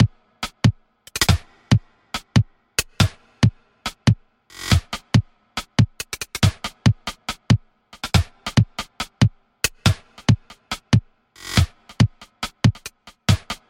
描述：像一个Dnb节拍，但它在140
Tag: 140 bpm Drum And Bass Loops Drum Loops 2.31 MB wav Key : Unknown